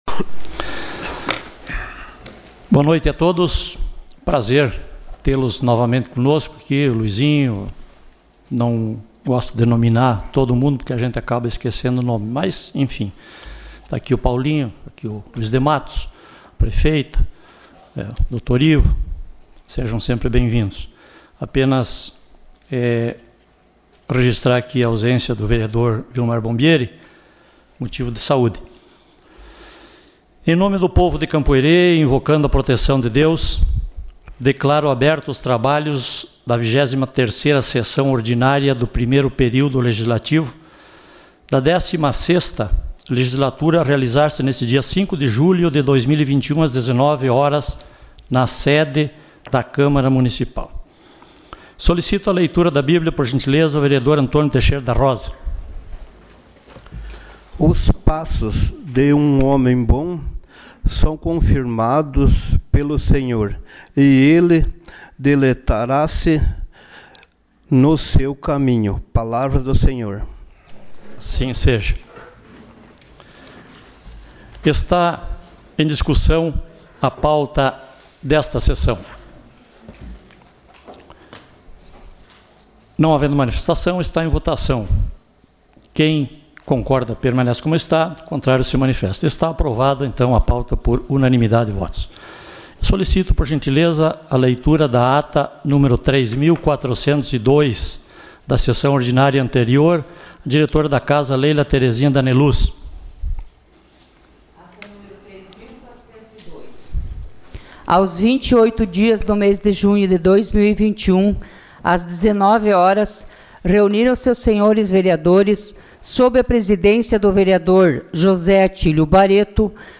Sessão Ordinária dia 05 de julho de 2021